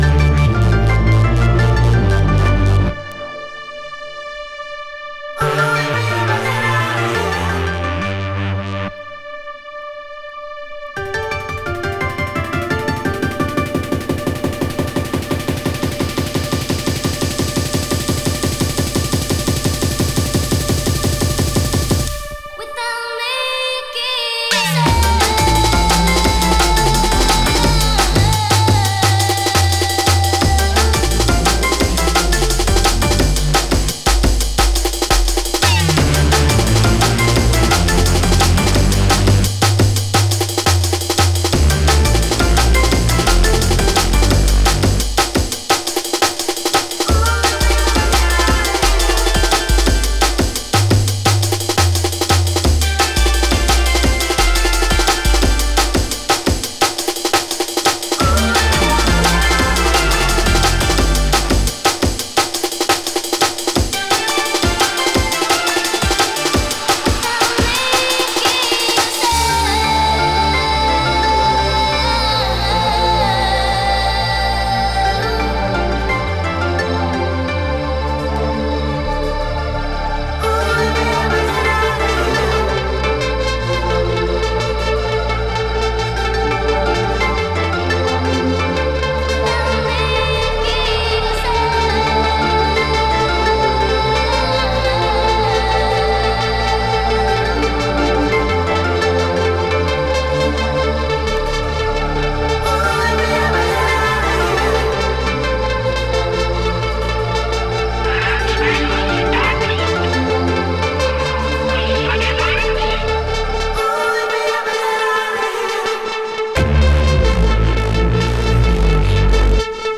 Styl: Techno Vyd�no